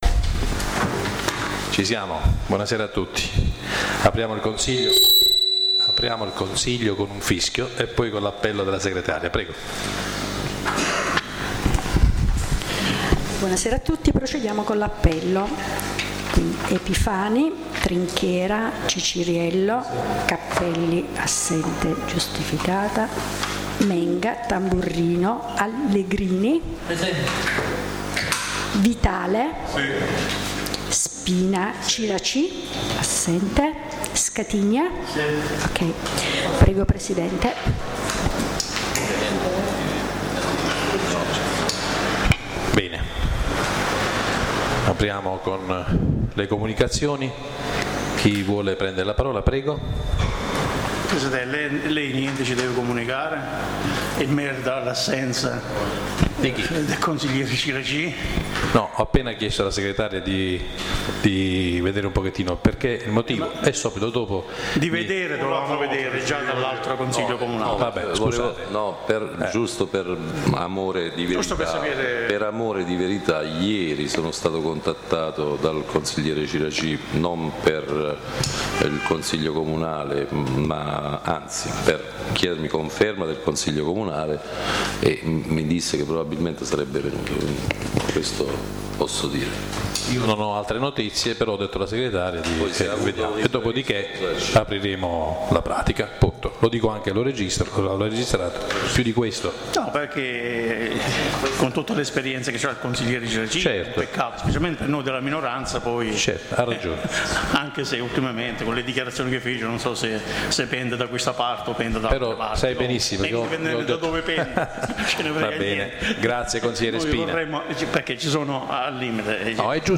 La registrazione audio del Consiglio Comunale di San Michele Salentino del 14/09/2016.